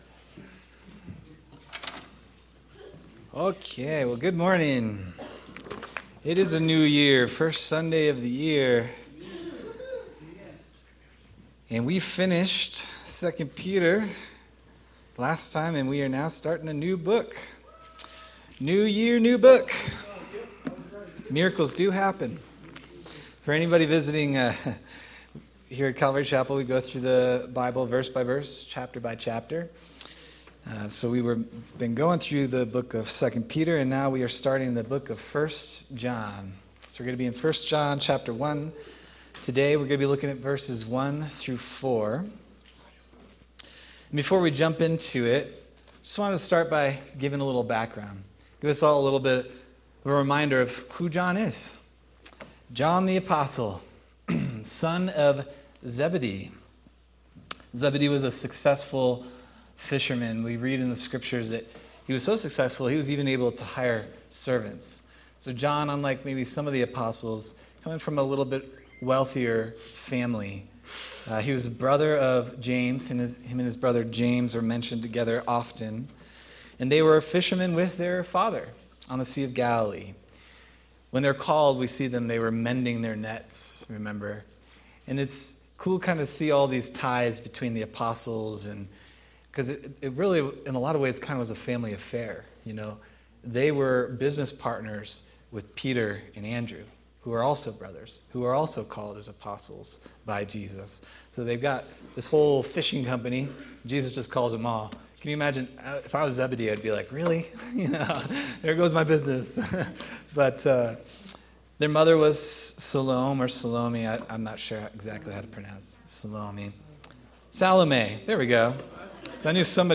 January 4th, 2026 Sermon